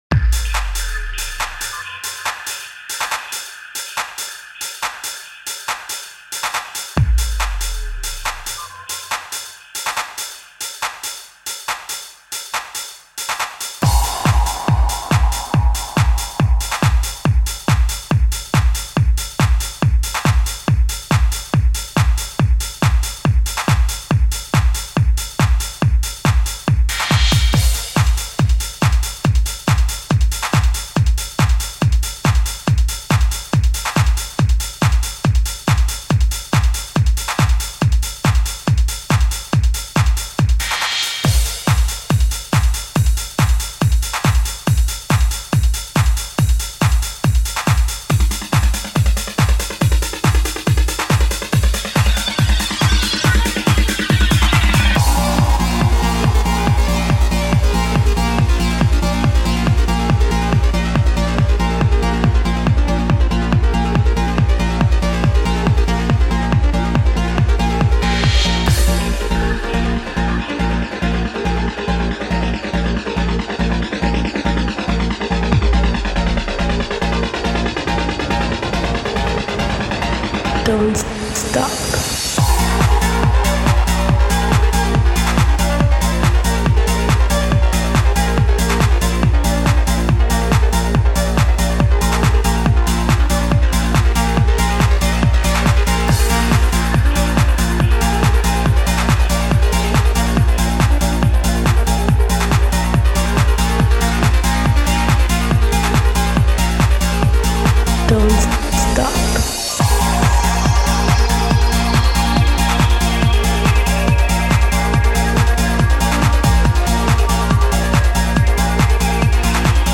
Жанр: Trance